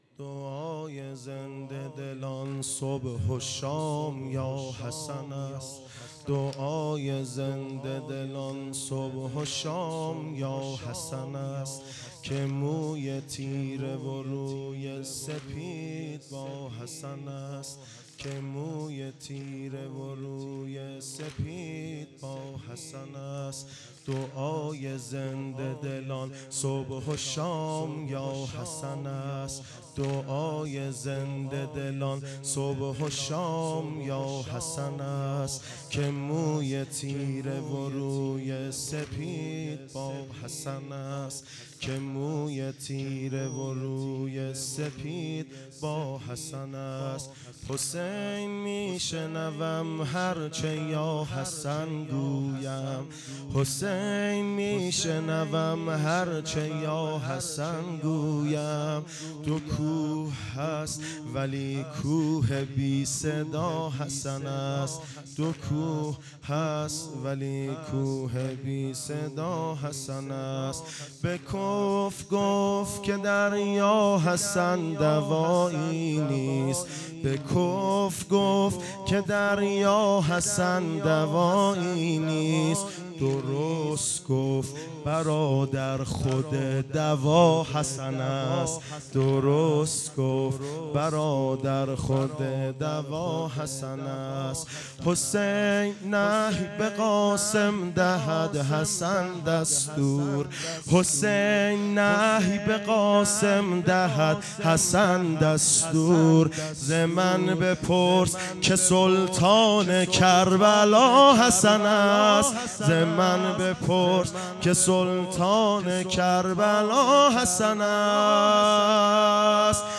هیات سائلین اباصالح المهدی عج - محرم ۱۴۰۲